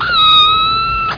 1 channel
kitten.mp3